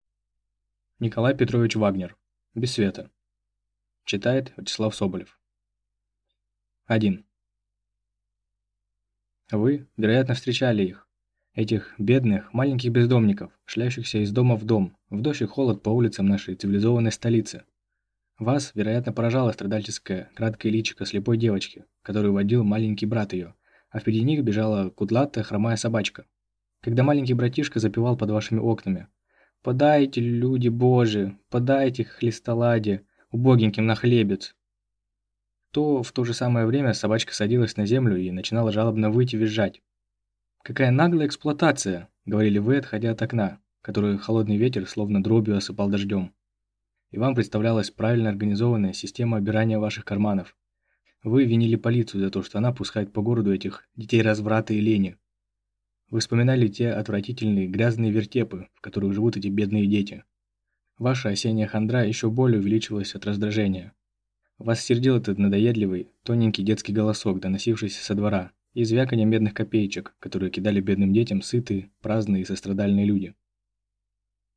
Аудиокнига Без света | Библиотека аудиокниг